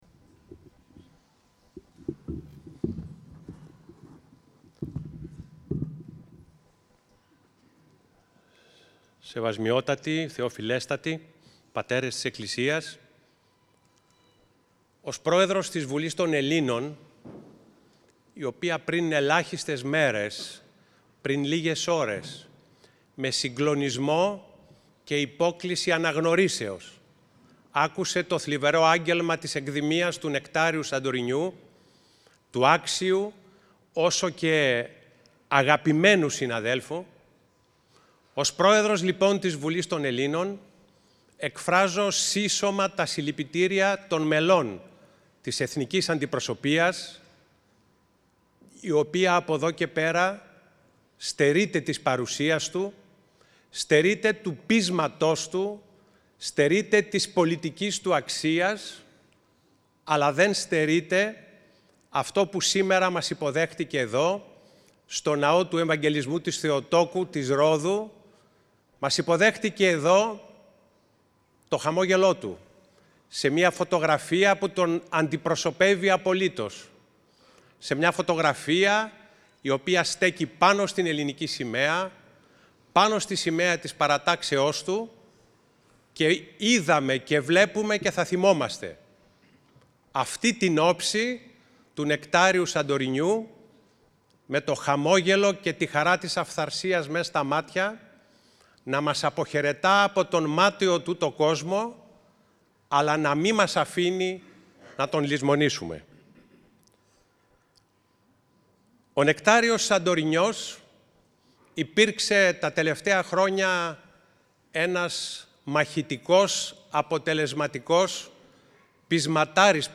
Ο επικήδειος λόγος του Προέδρου της Βουλής των Ελλήνων